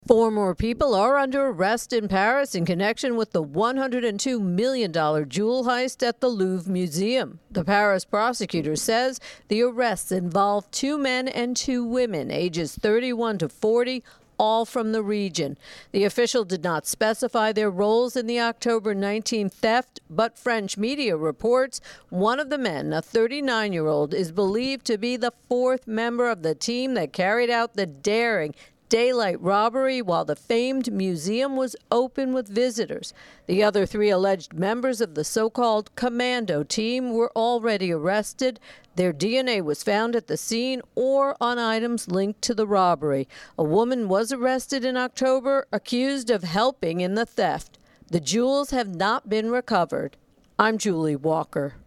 reports on more arrests in that $102M Louvre jewel heist.